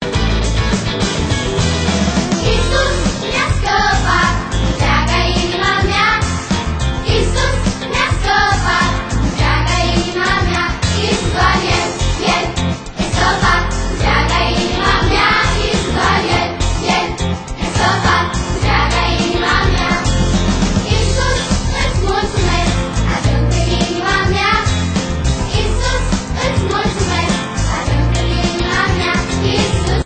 Cantece de inchinare pentru copii